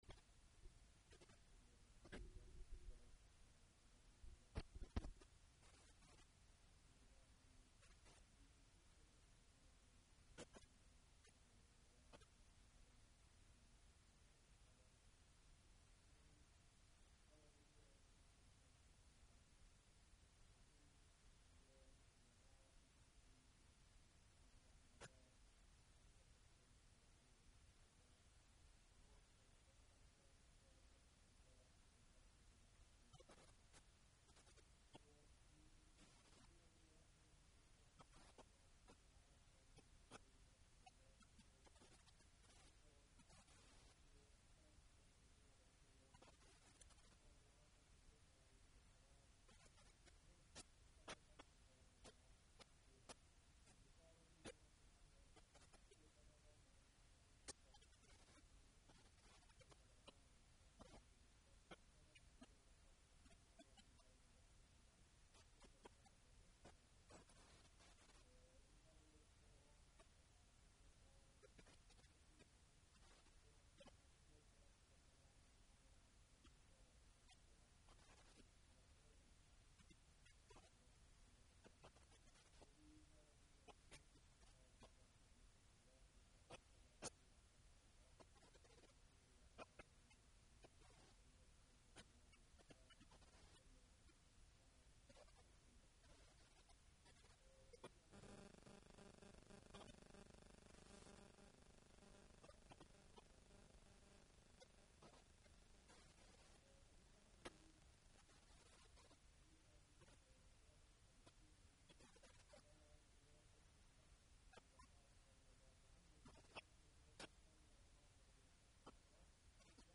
En föreläsning av